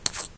chipsHandle4.ogg